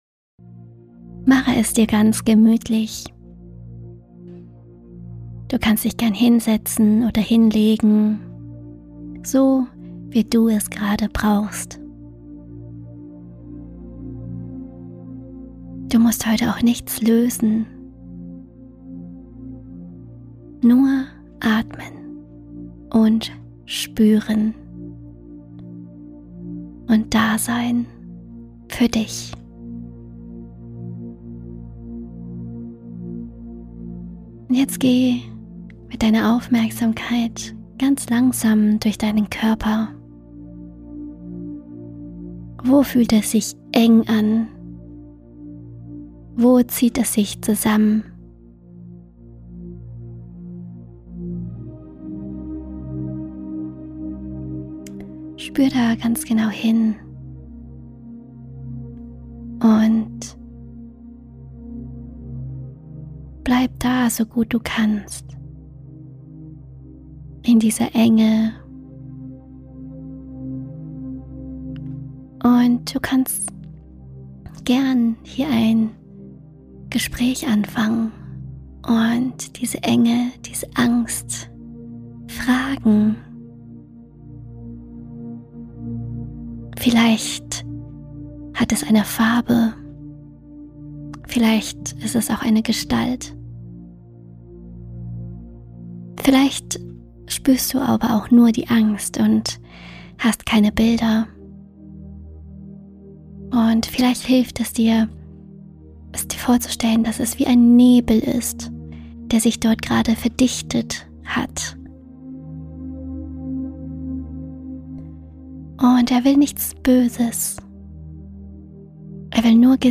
Begleitende Meditation zu Folge 3